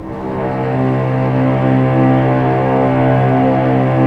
Index of /90_sSampleCDs/Roland L-CD702/VOL-1/STR_Vcs Bow FX/STR_Vcs Sul Pont